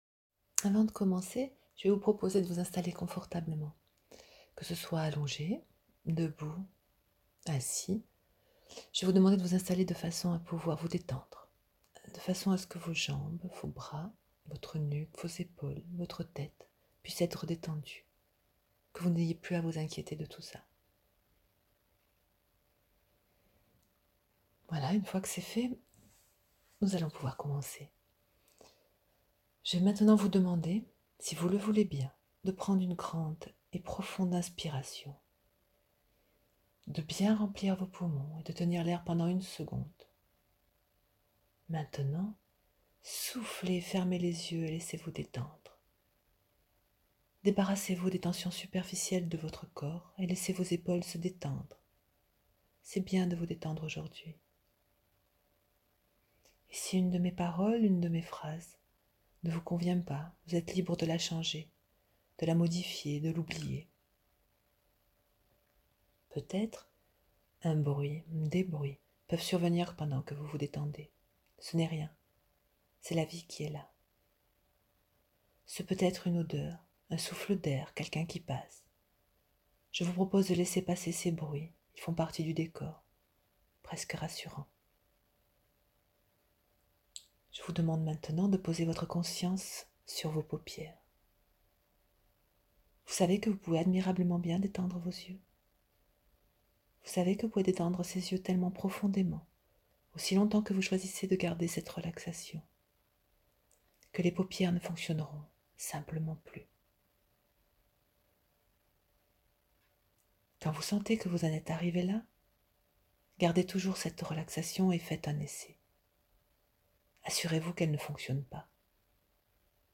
Hypnose